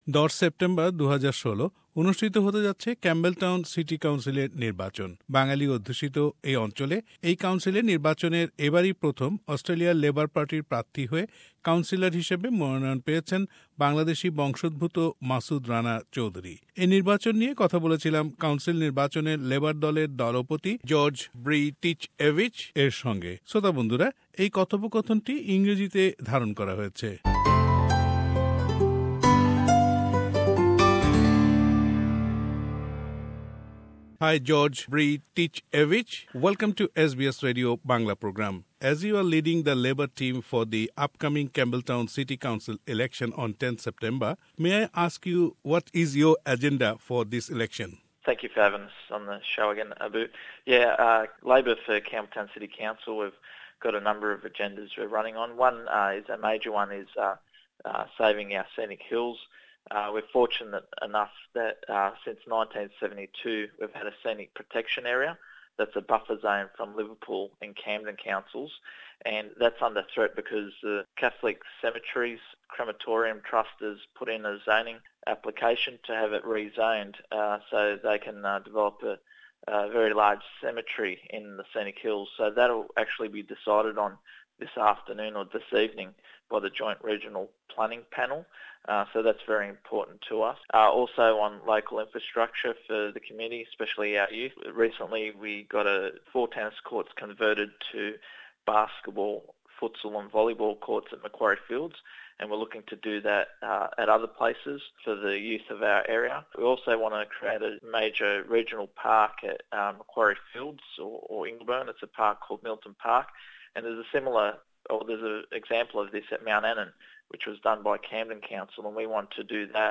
Campbell town city council election : Interview